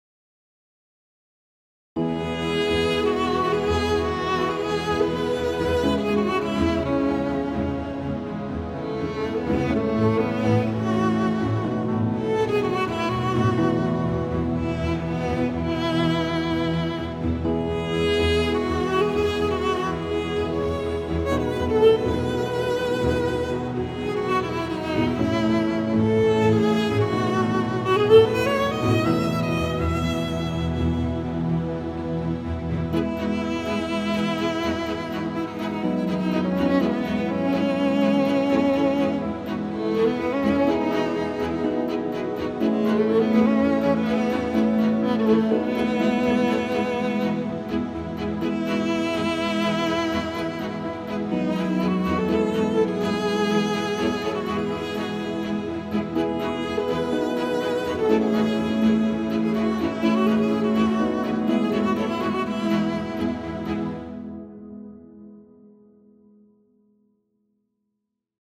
A collection of symphonic tracks